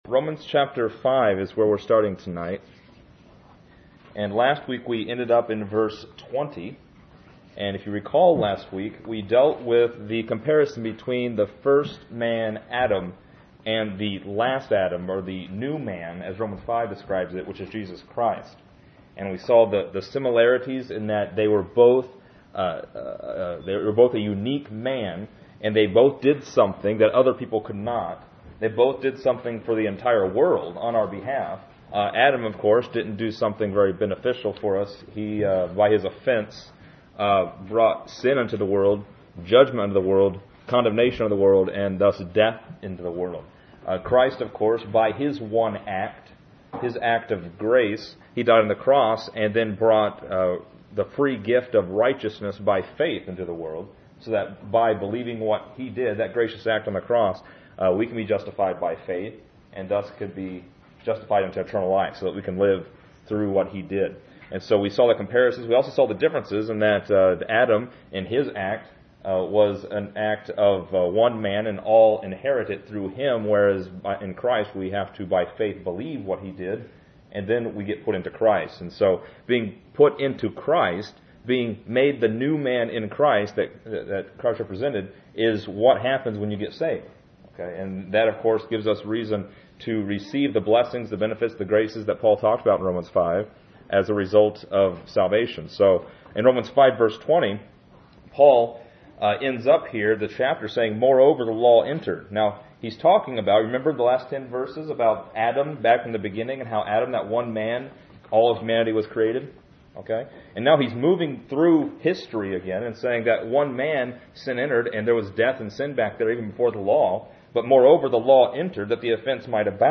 This lesson is part 22 in a verse by verse study through Romans titled: Baptized Into Christ.